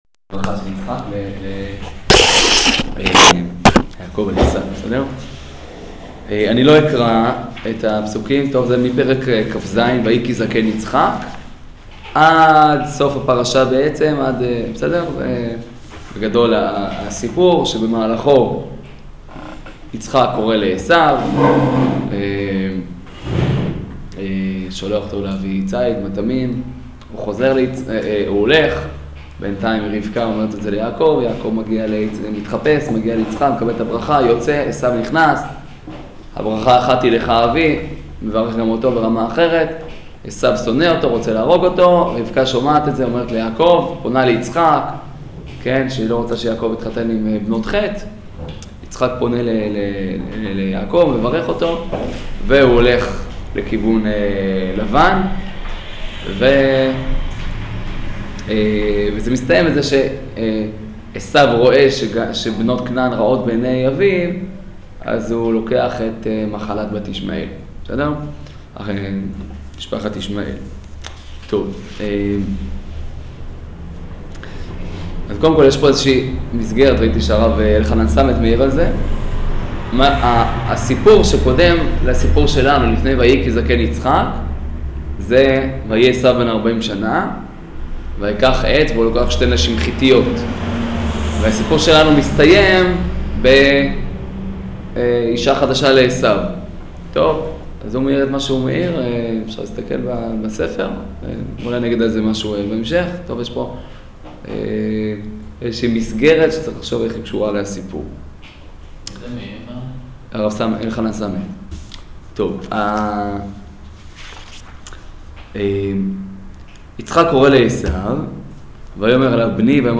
שיעור פרשת תולדות